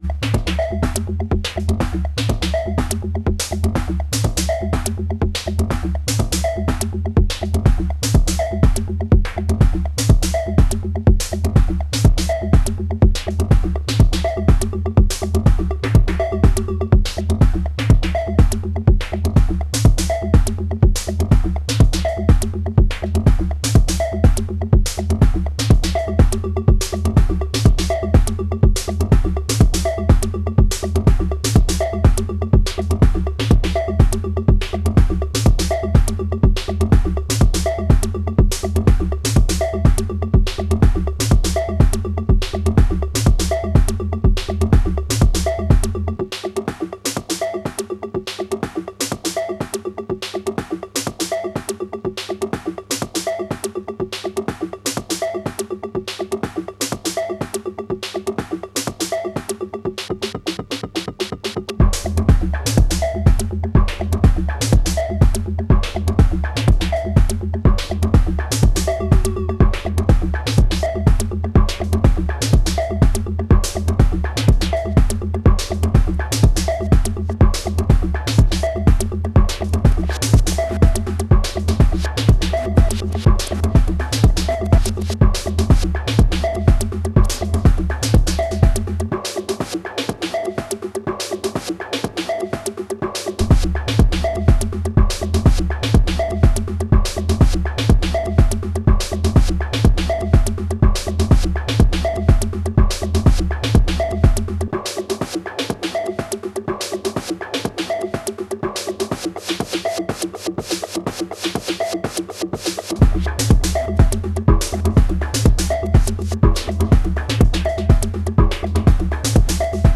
Volca Kick, Drum & Digitone with the OT as a djstyle mixer/resampler.